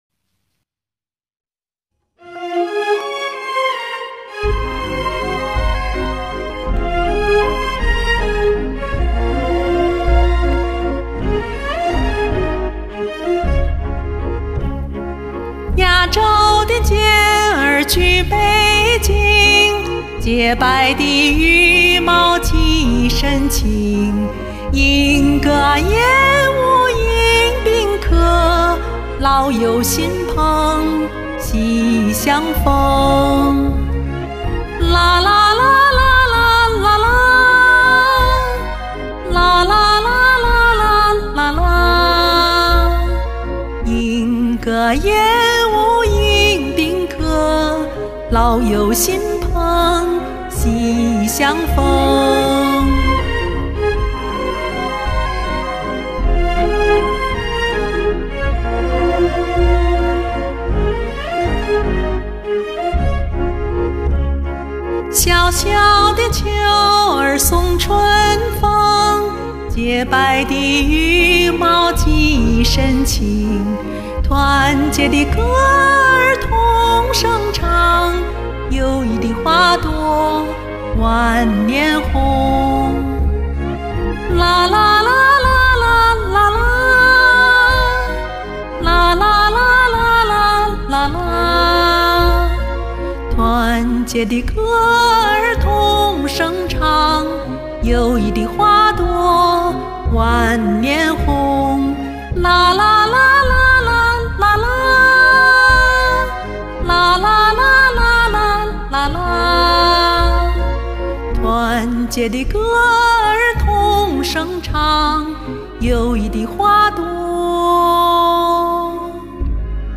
这首歌节奏欢快、旋律优美
声音清亮明澈真好听